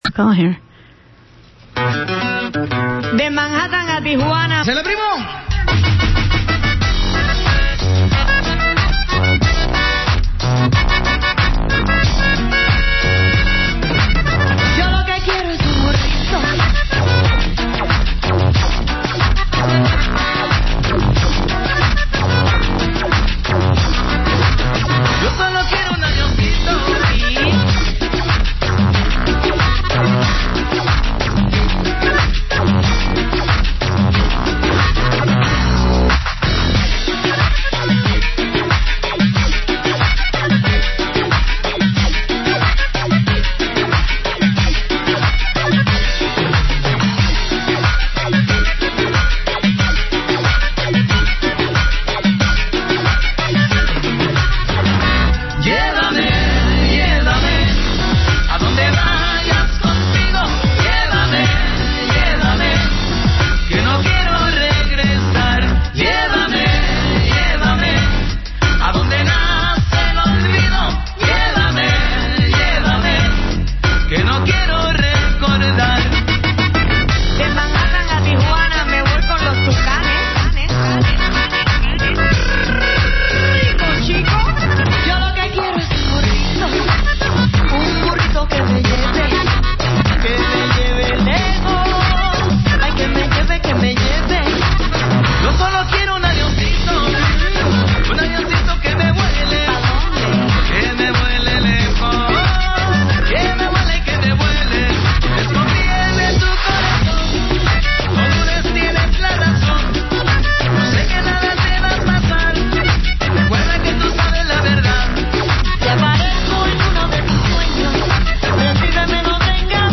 Two weeks ago I was a guest on KPFA’s Ritmo de las Americas.